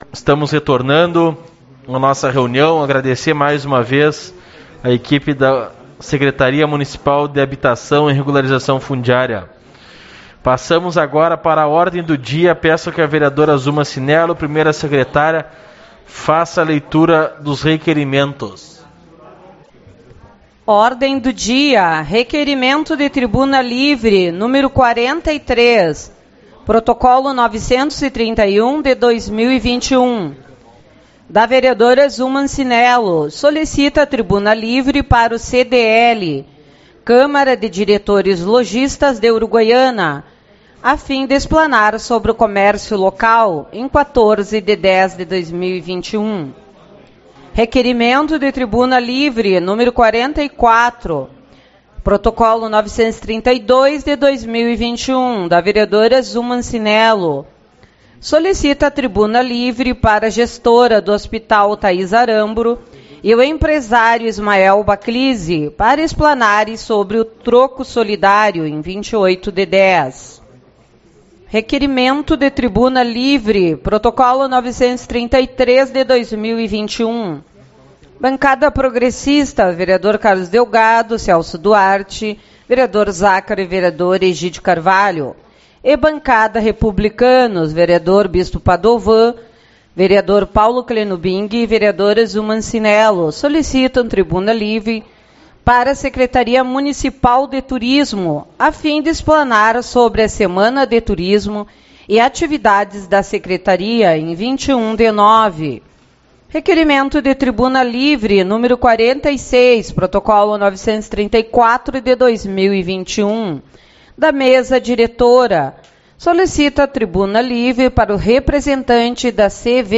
02/09 - Reunião Ordinária